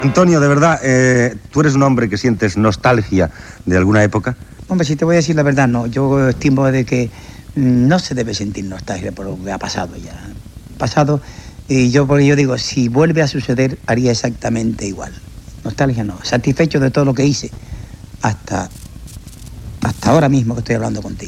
Entrevista al cantant cubà Antonio Machín.